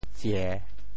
照（炤） 部首拼音 部首 灬 总笔划 13 部外笔划 9 普通话 zhào 潮州发音 潮州 zieu3 文 ziê3 白 潮阳 zio3 ziao3 澄海 ziê3 揭阳 zio3 饶平 zio3 汕头 zio3 中文解释 照 <形> (形声。
tsie3.mp3